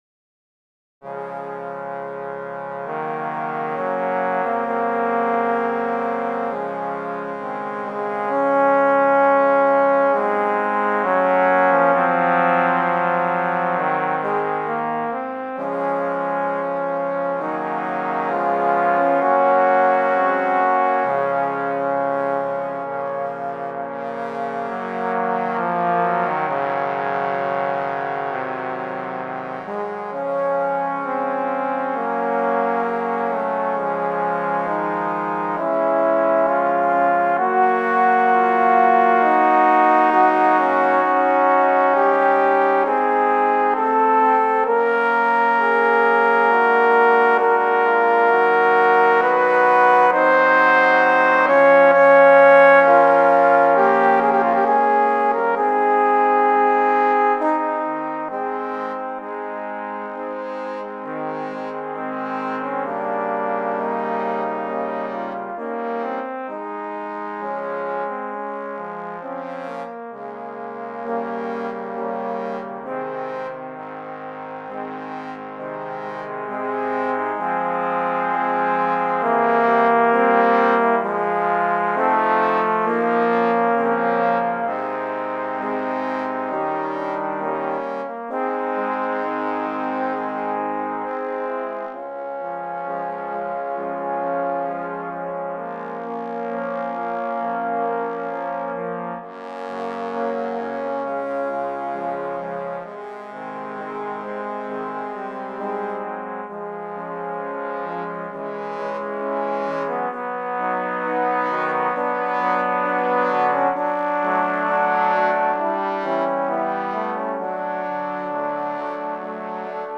Voicing: Tbn. Choir